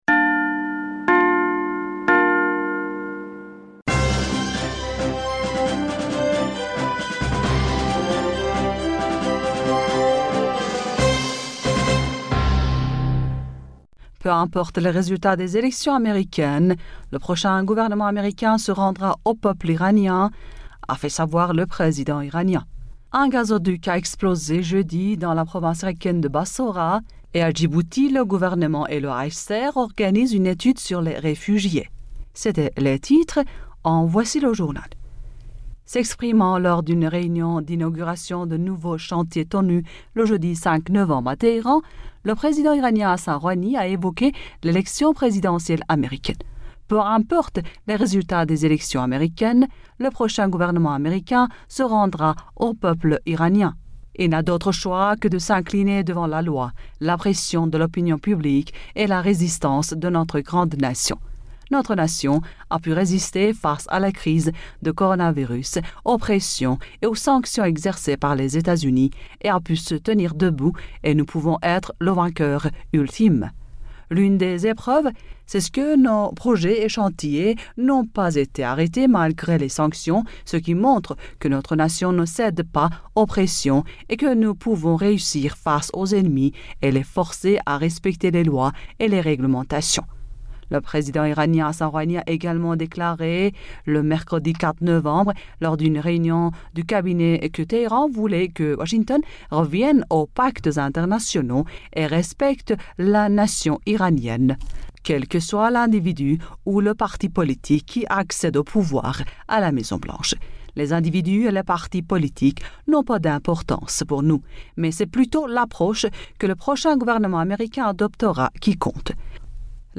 Bulletin d'informationd u 06 November 2020